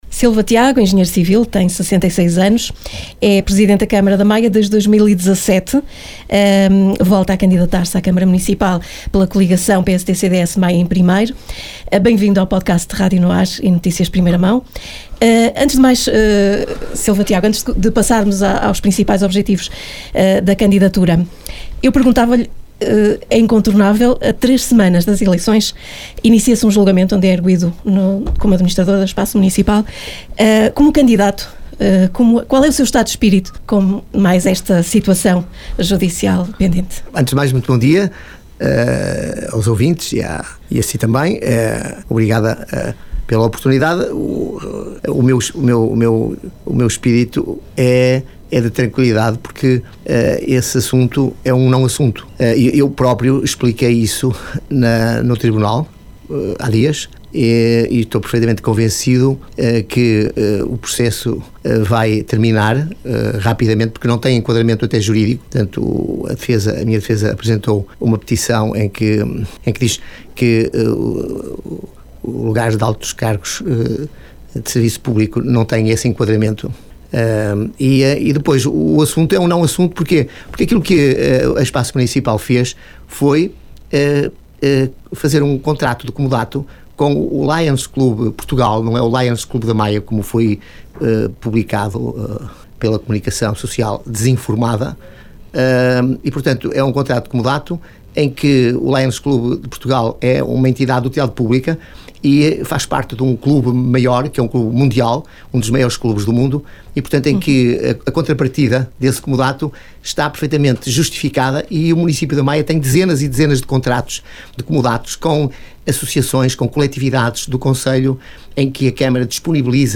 Entrevista-Silva-Tiago-PSD-CDS-Maia_SET2025.mp3